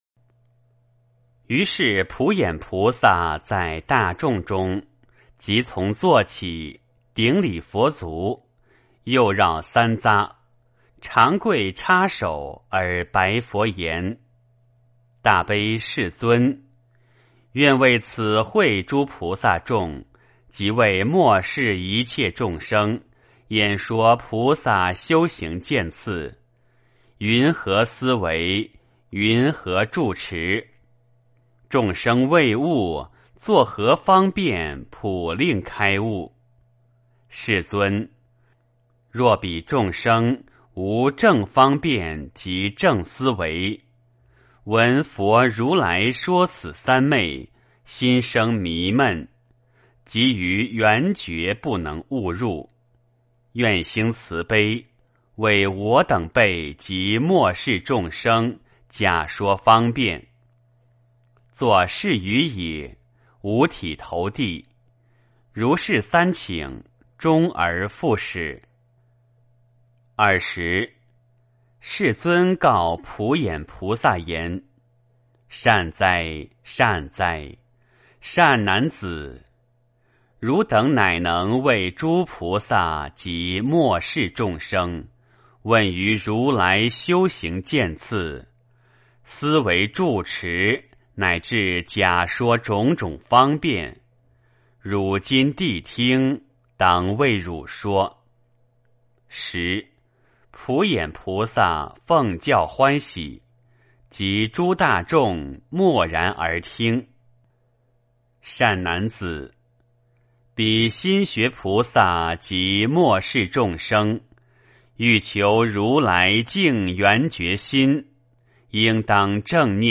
圆觉经-03普眼菩萨 诵经 圆觉经-03普眼菩萨--未知 点我： 标签: 佛音 诵经 佛教音乐 返回列表 上一篇： 圆觉经-02普贤菩萨 下一篇： 圆觉经-05弥勒菩萨 相关文章 准提咒(童声)--未知 准提咒(童声)--未知...